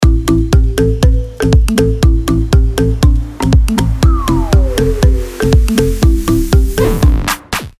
ритмичные
веселые
без слов
house
озорные